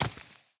Minecraft Version Minecraft Version snapshot Latest Release | Latest Snapshot snapshot / assets / minecraft / sounds / mob / husk / step5.ogg Compare With Compare With Latest Release | Latest Snapshot
step5.ogg